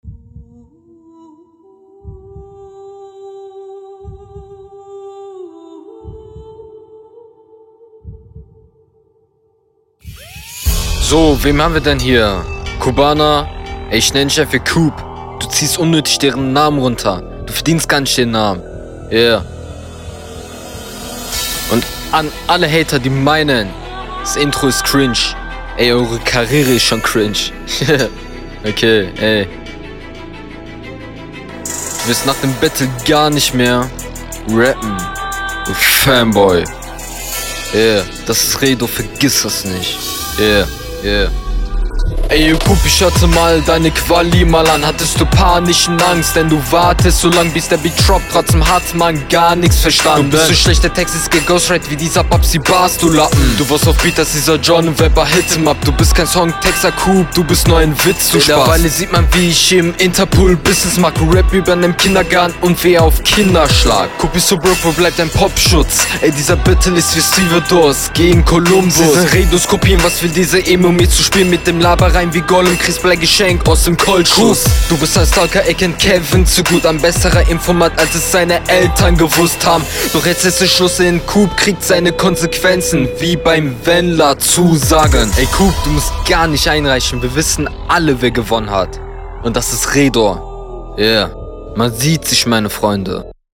Battle Runden
Teils komische Formulierungen und auch kein wirklich nicer flow.